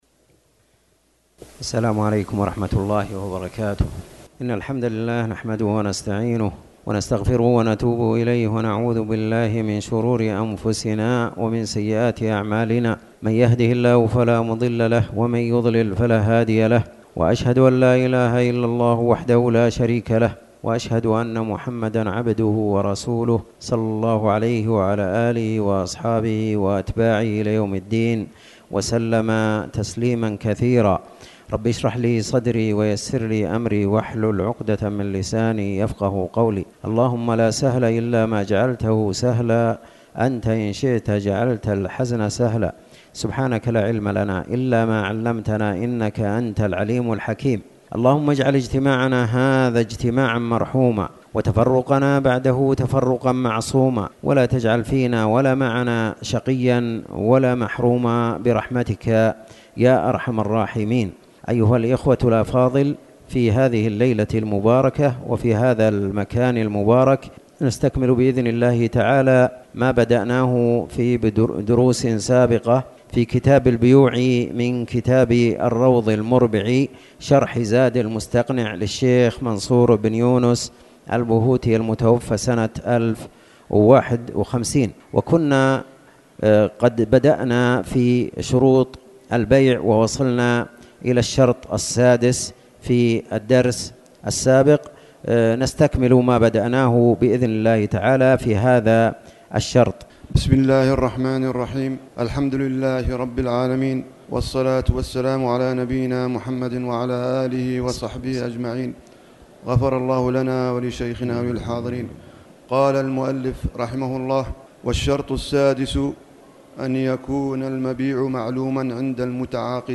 تاريخ النشر ٦ جمادى الأولى ١٤٣٩ هـ المكان: المسجد الحرام الشيخ